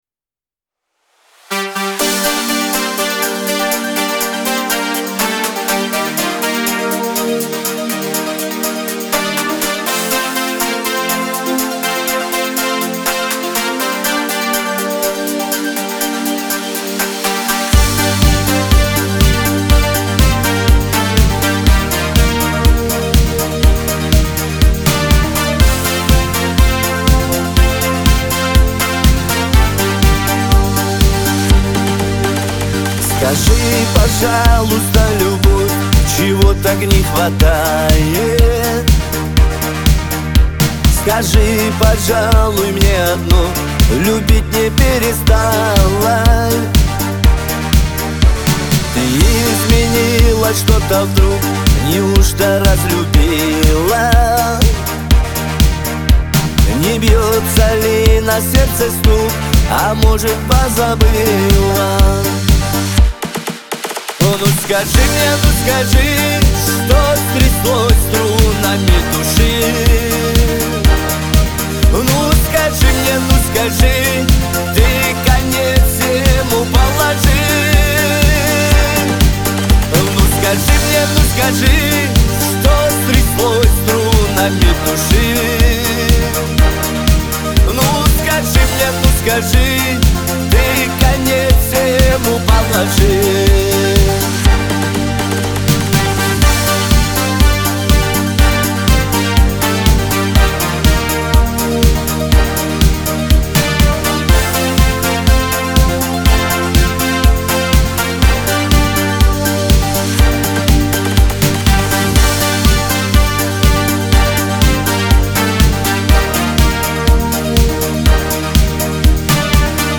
Главная ➣ Жанры ➣ Кавказ поп. 2025.